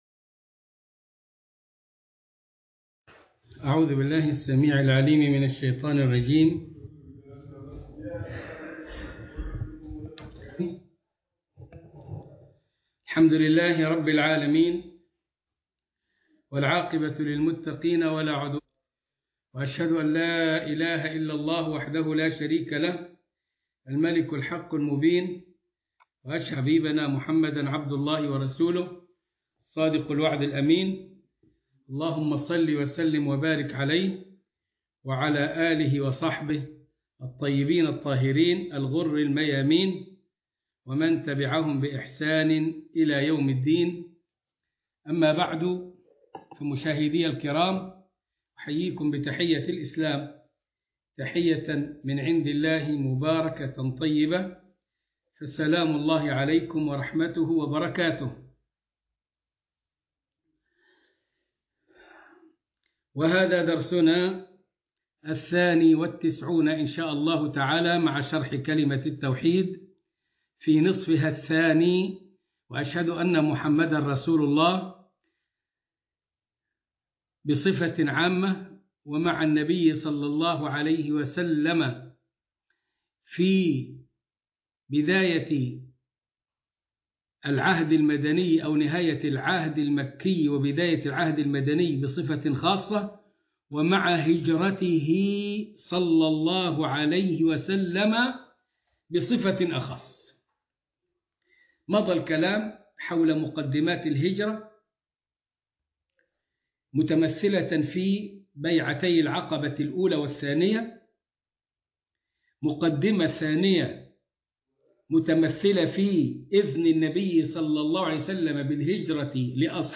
المحاضرة الثانية والتسعون